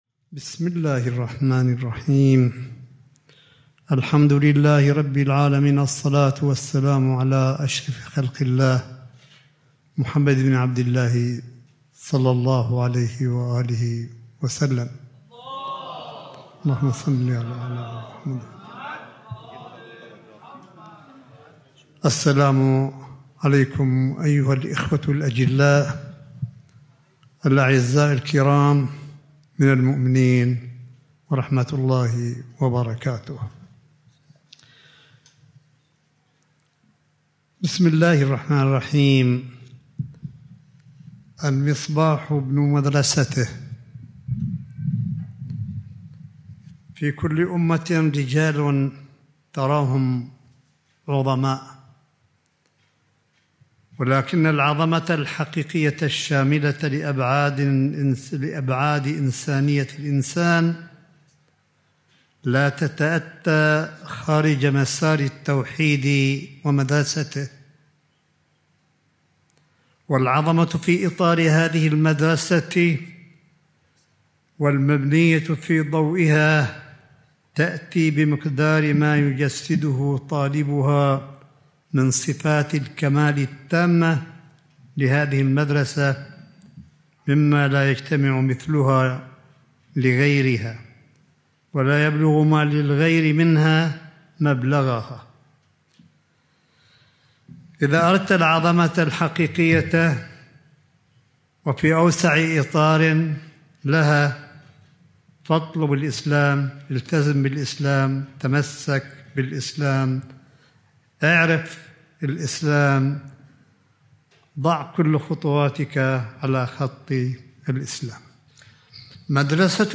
ملف صوتي لكلمة سماحة آية الله الشيخ عيسى أحمد قاسم في الملتقى التعريفي بفكر الفيلسوف الراحل سماحة آية الله الشيخ محمد تقي المصباح اليزدي، والذي أقيم في قمّ المقدسة يوم الأربعاء 10 مارس 2021م